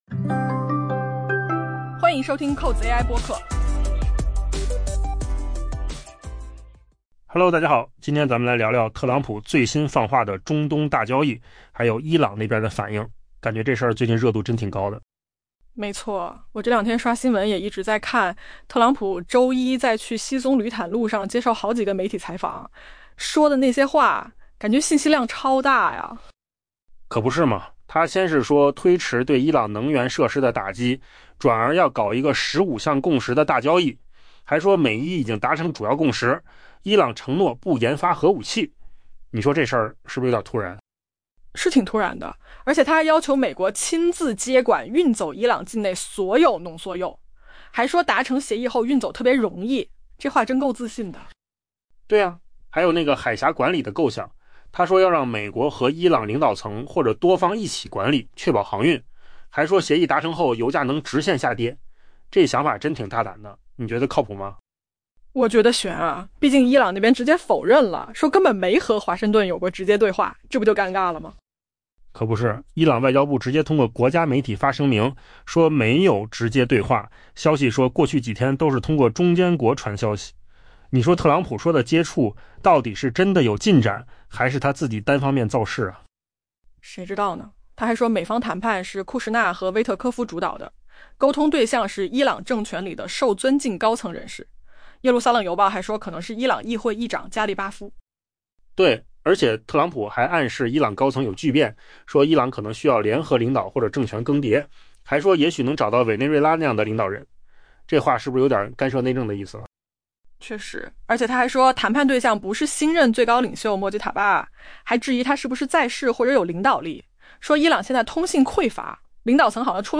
AI播客：换个方式听新闻 下载mp3 音频由扣子空间生成 美国总统特朗普周一在前往西棕榈滩途中，密集接受了媒体采访，释放出中东局势可能发生结构性逆转的强烈信号。